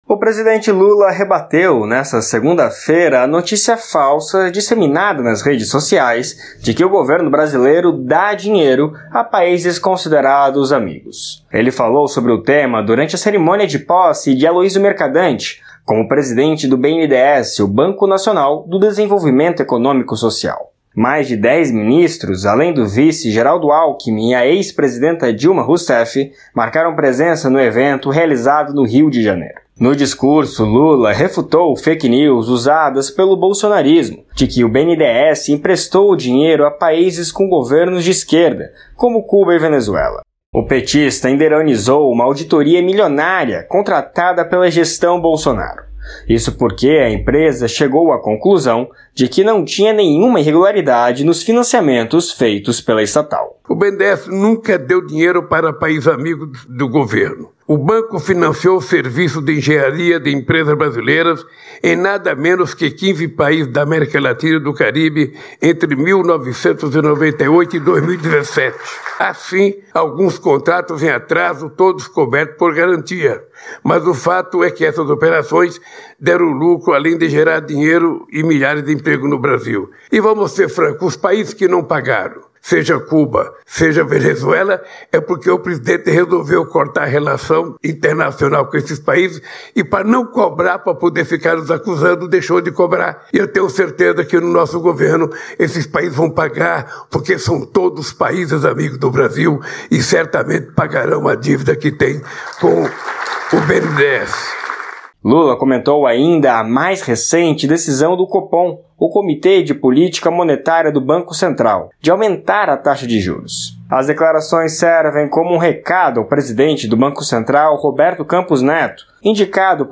Cerimônia com a presença de Lula (PT) ocorreu na sede do banco, no Rio de Janeiro - Reprodução/YouTube